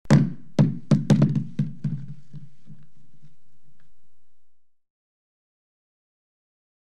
Звуки яблока
Яблоко упало на пол из дерева и покатилось